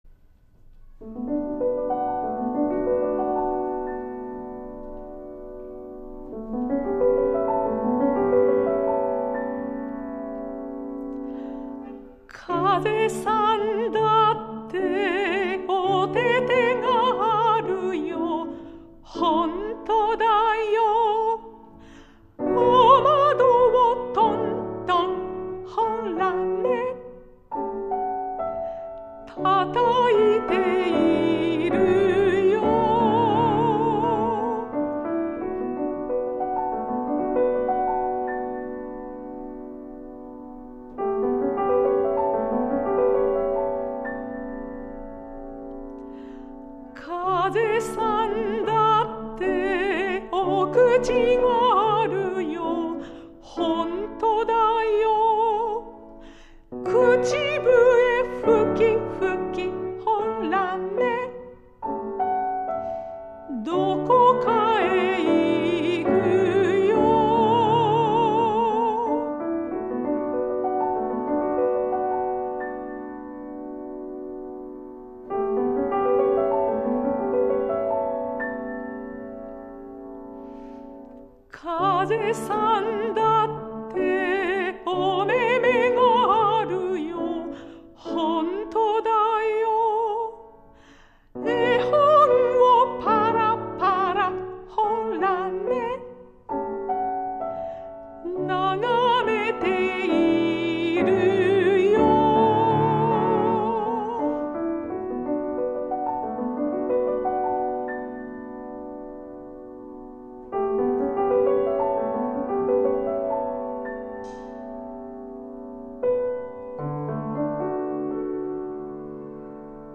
メゾ・ソプラノ
ピアノ